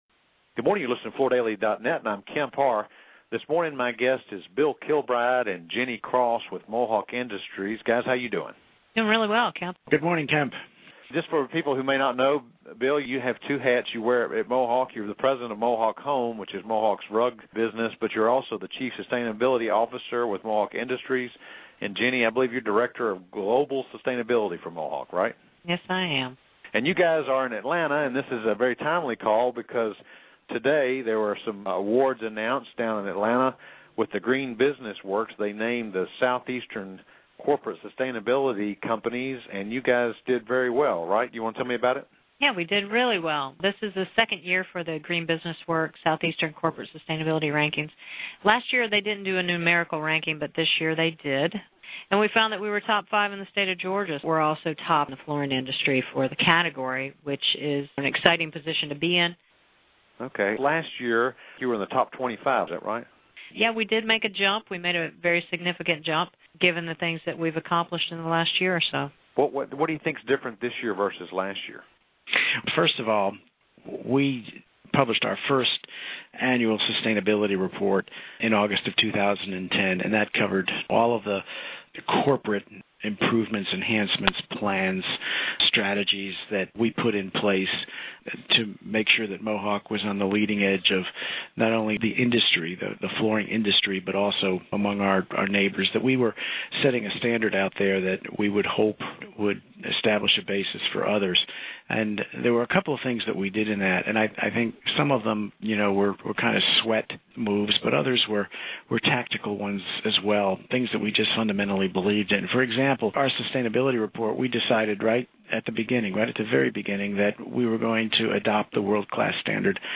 Listen to the interview to hear details of how Mohawk was picked for this honor and where sustainability fits in Mohawk's strategic plan.